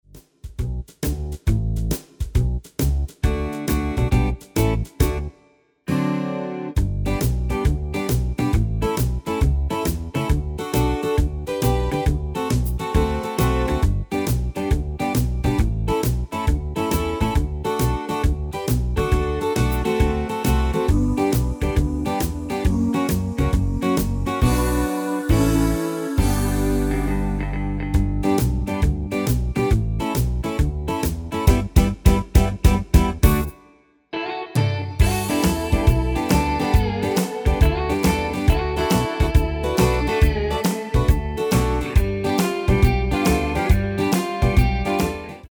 Demo/Koop midifile
Genre: Rock & Roll / Boogie / Twist / Rockabilly
Toonsoort: D
- Vocal harmony tracks
Demo's zijn eigen opnames van onze digitale arrangementen.